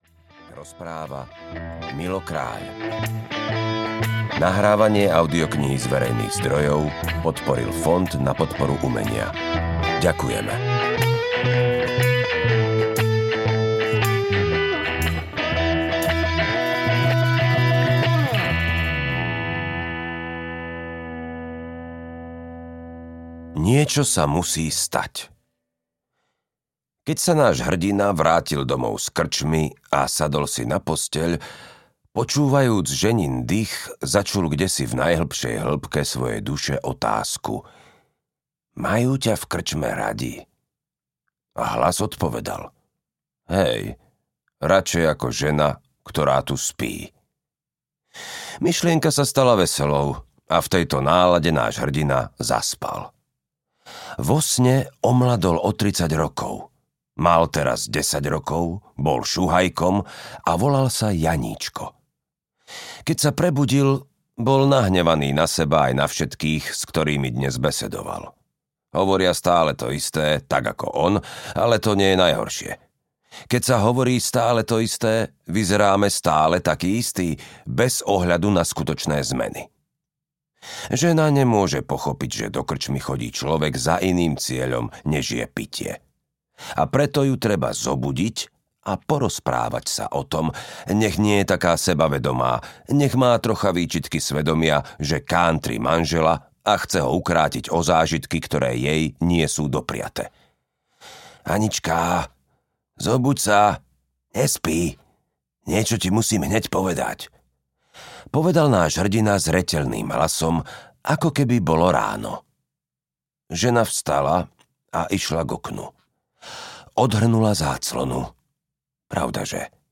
Pánsky flám audiokniha
Ukázka z knihy
pansky-flam-audiokniha